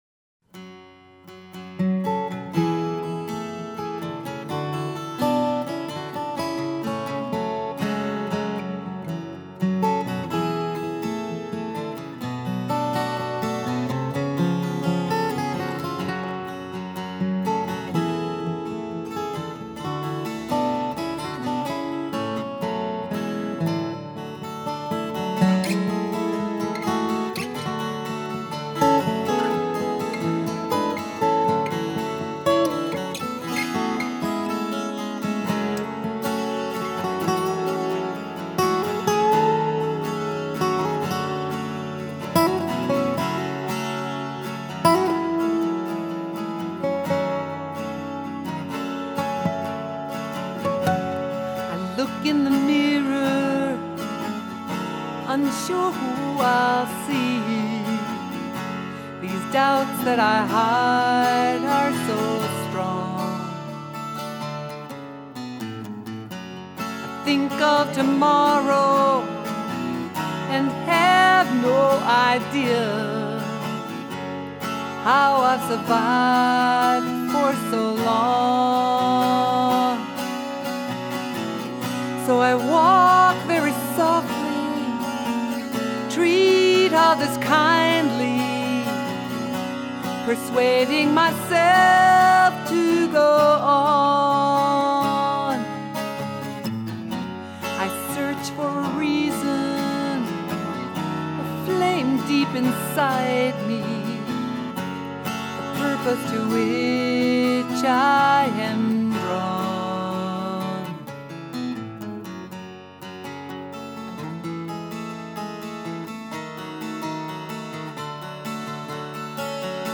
An accoustic ballad.